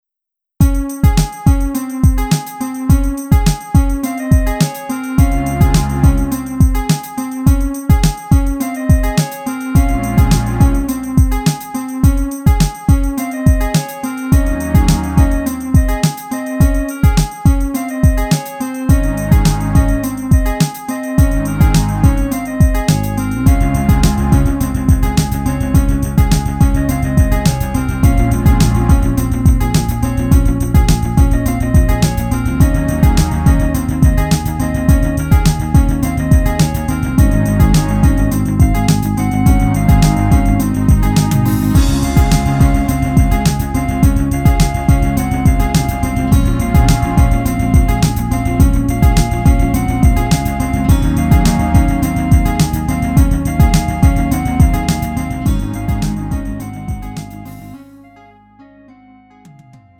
음정 -1키 3:29
장르 구분 Lite MR